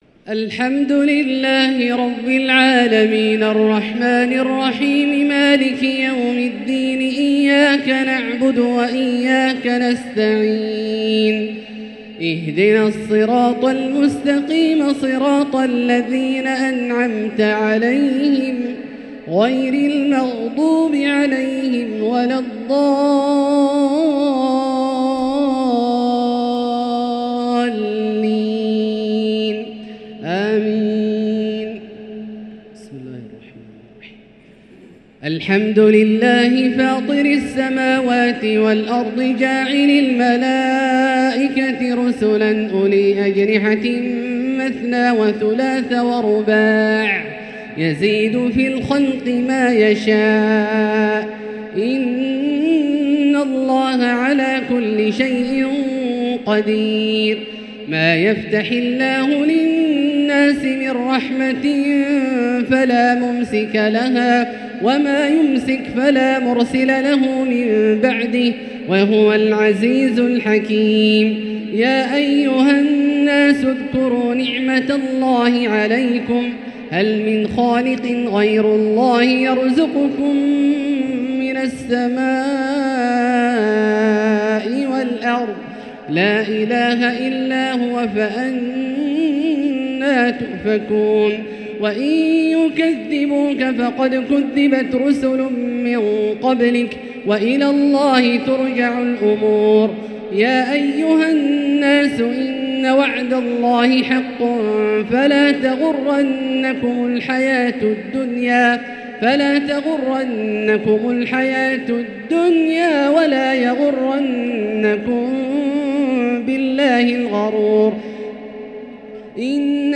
تراويح ليلة 25 رمضان 1444هـ من سورتي فاطر و يس (1-54) | taraweeh 25 st night Ramadan 1444H Surah Faatir and Yaseen > تراويح الحرم المكي عام 1444 🕋 > التراويح - تلاوات الحرمين